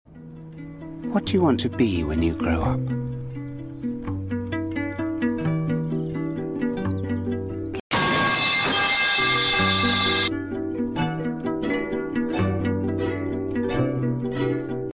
Metal pipe
jumpscare warning